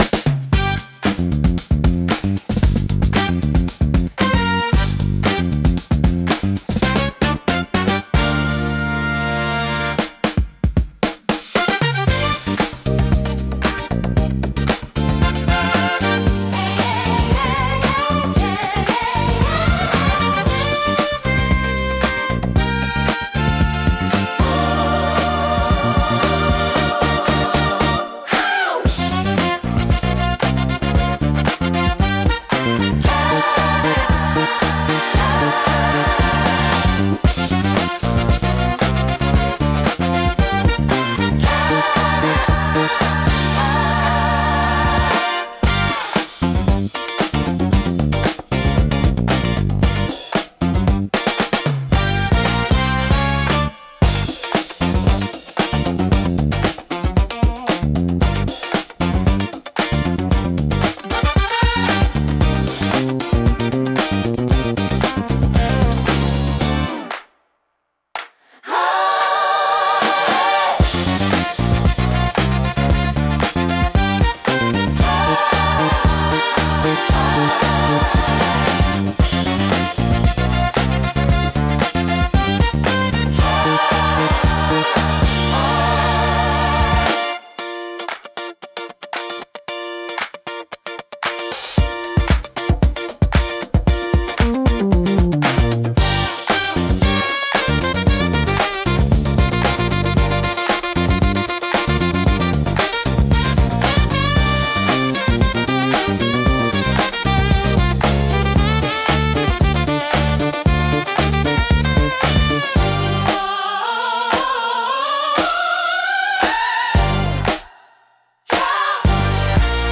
心地よいリズムが聴く人を踊らせ、楽しい雰囲気を盛り上げる効果があります。躍動感と活気に満ちたジャンルです。